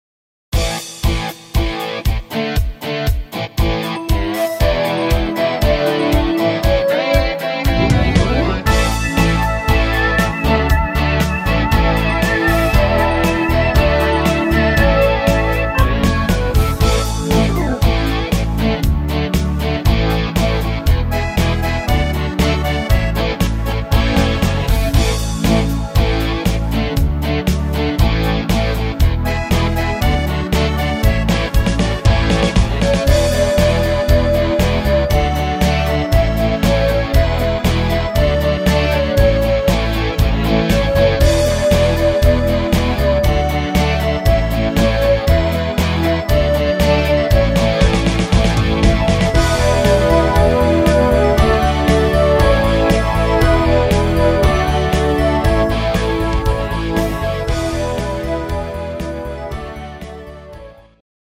Playback abmischen  Playbacks selbst abmischen!
Rhythmus  Alpen Rock
Art  Volkstümlich, Deutsch